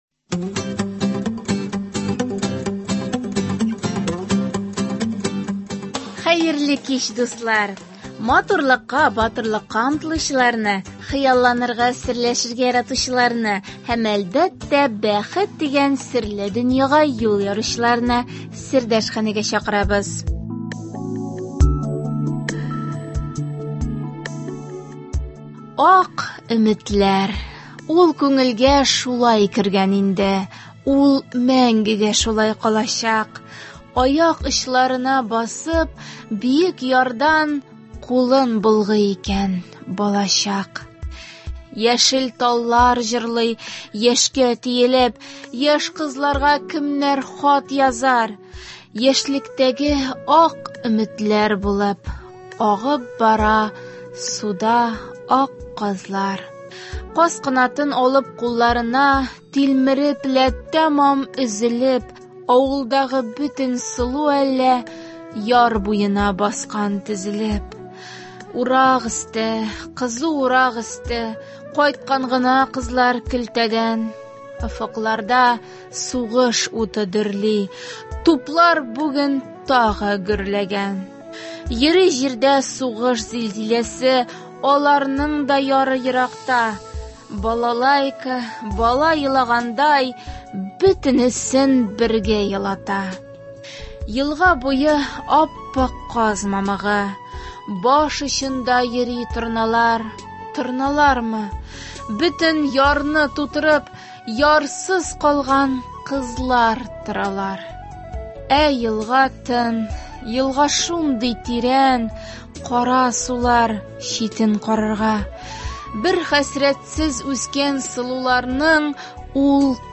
Тапшыру барышында сез аның яшүсмерләр өчен язылган шигырьләрен ишетерсез.